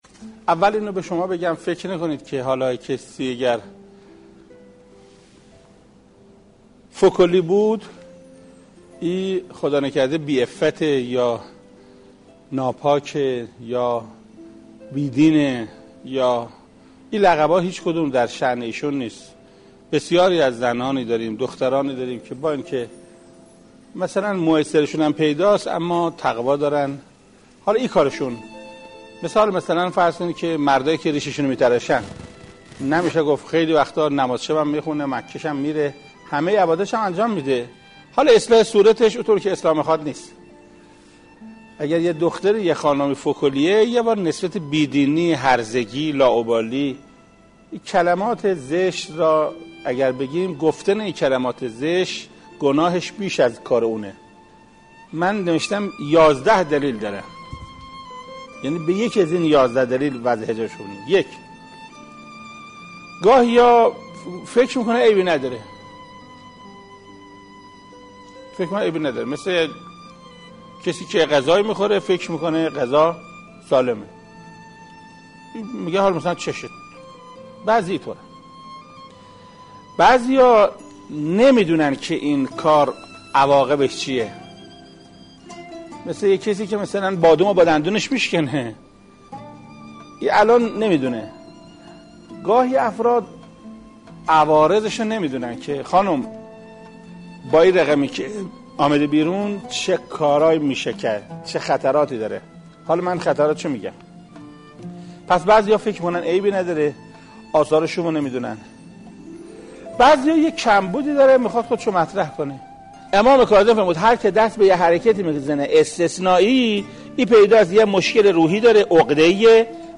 حجت‌الاسلام والمسلمین محسن قرائتی، مفسر قرآن کریم و رئیس ستاد اقامه نماز کشور با تأکید بر اینکه هر کسی که بدحجاب است را نباید بدون عفت بدانیم، به تبیین دلایل علت بی‌حجابی را پرداخت.